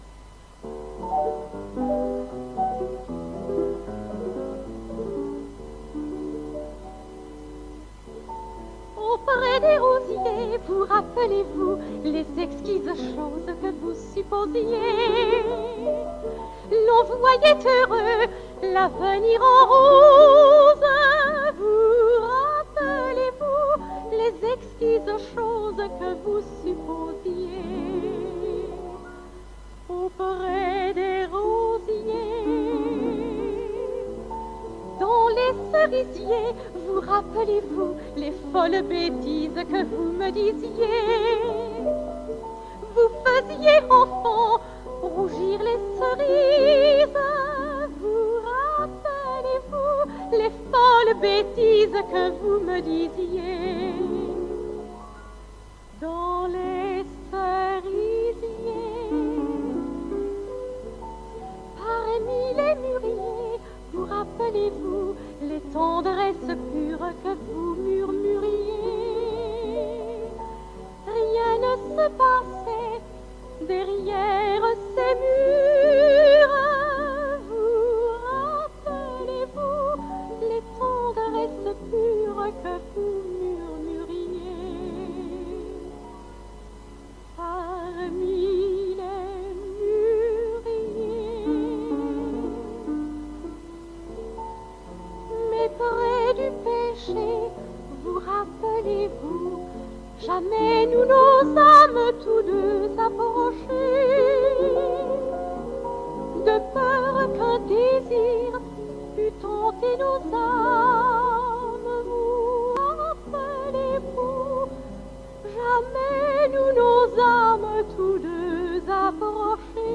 POUR LA RADIO BELGE
EN CONCERT
le compositeur a accompagné